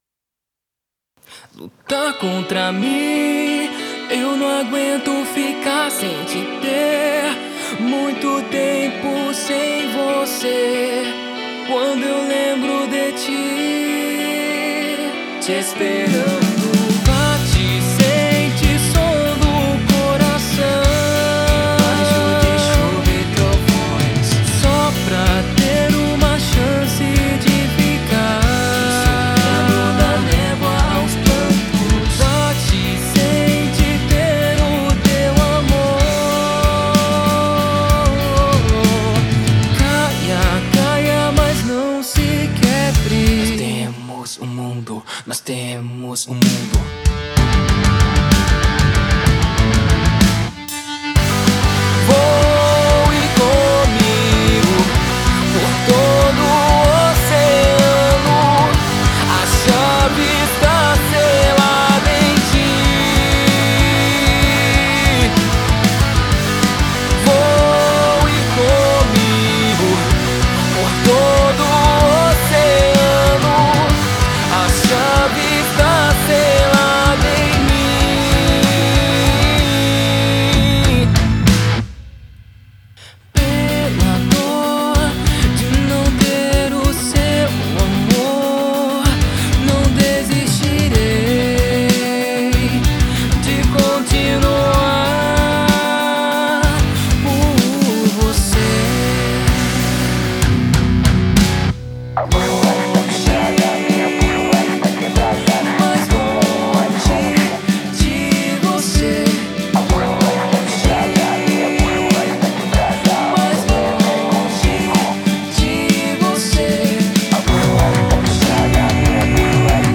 Рок/метал Микс на оценку.